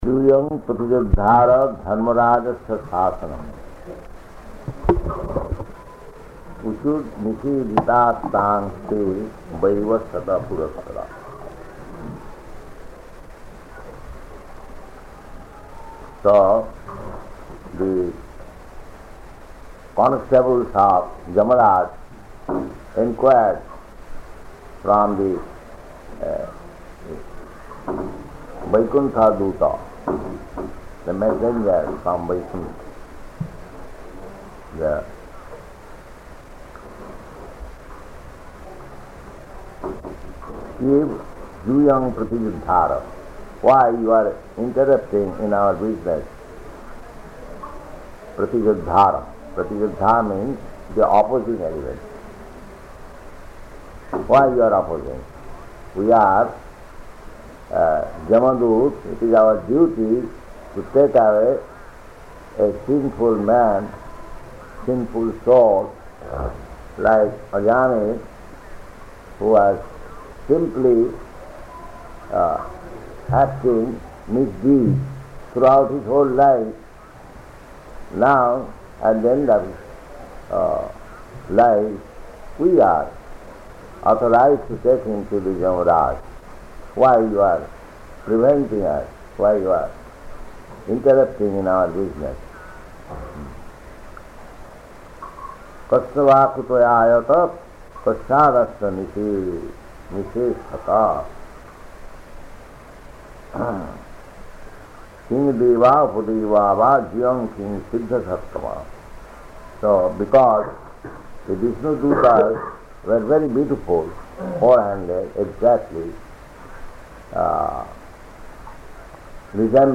Location: Surat